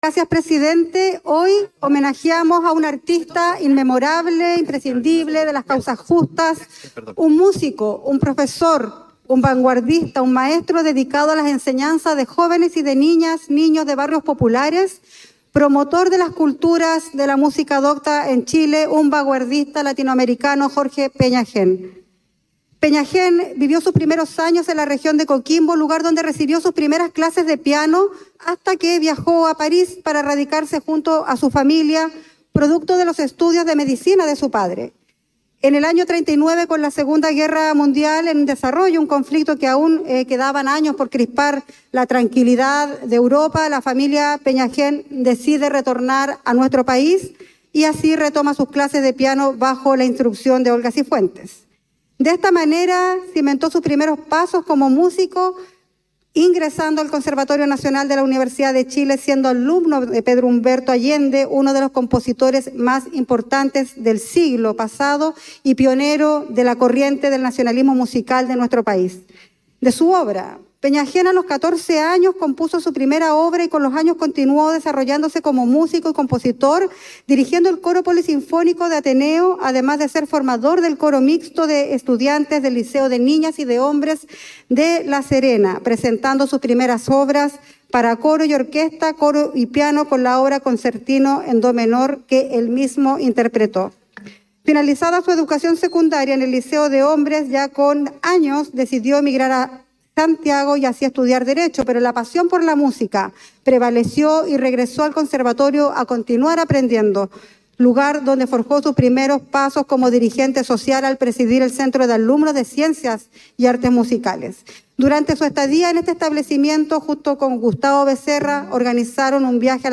Durante la jornada de este miércoles, la Cámara de Diputadas y Diputados rindió un homenaje al destacado compositor y maestro de las artes musicales de la región de Coquimbo y el resto del país, Jorge Peña Hen.
La ceremonia se dio por iniciada con palabras de la diputada Nathalie Castillo -quien solicitó hace algunos días al resto de los Comités partidarios el desarrollo de esta instancia- y se enmarca en memoria de su fallecimiento a manos de militares el 16 de octubre de 1973, en el ex Regimiento Arica de La Serena, junto a otros 14 presos políticos.